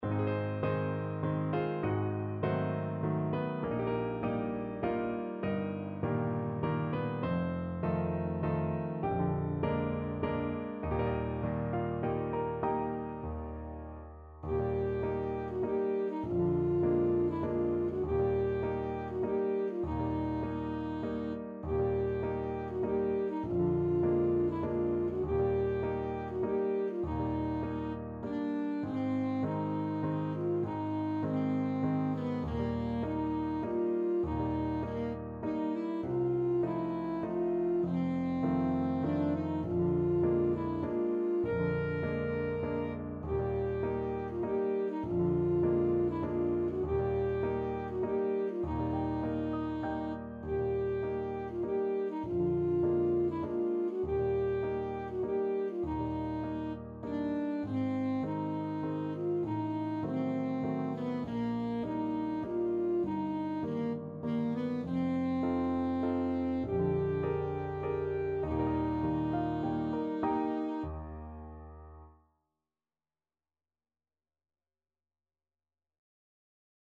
Alto Saxophone
3/4 (View more 3/4 Music)
~ = 100 Slowly and dreamily
Bb4-Bb5
Classical (View more Classical Saxophone Music)